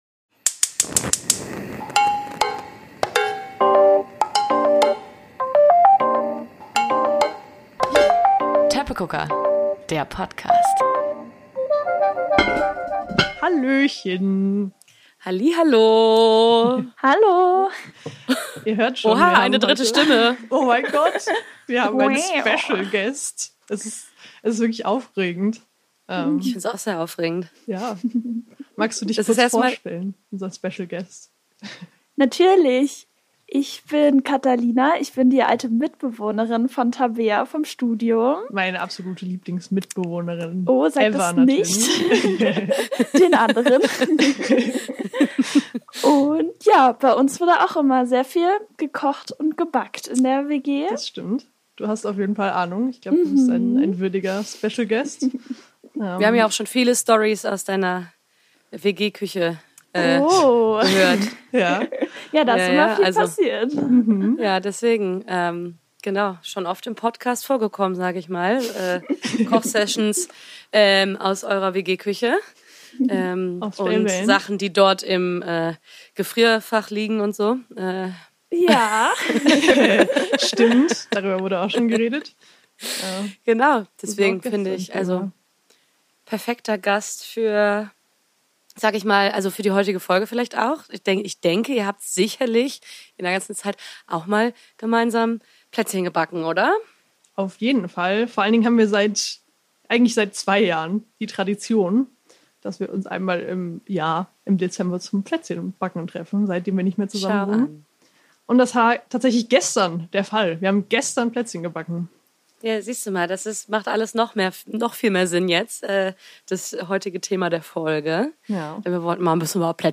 Und dieses mal sogar mit drei Stimmen anstatt nur zwei.